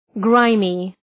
Προφορά
{‘graımı}